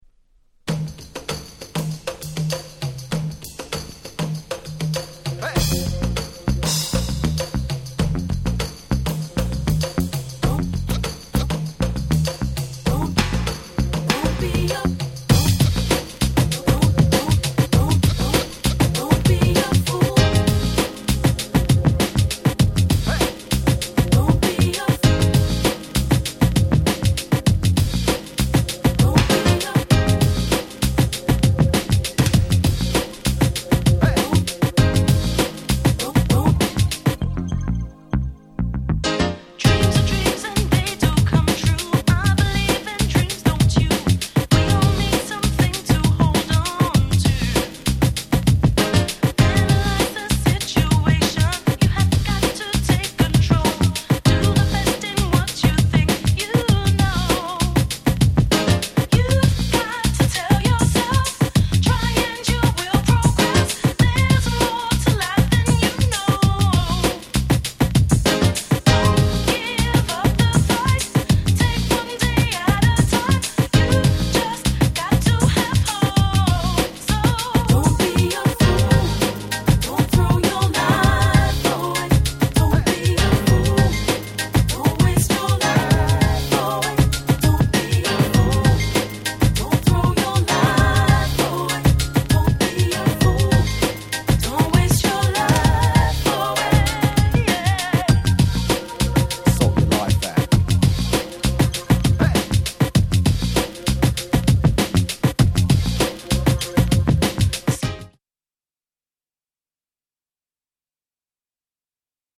※試聴ファイルは別の盤から録音してあります。この盤に冒頭のプチノイズ等は入りません。
UK Soul Super Classics !!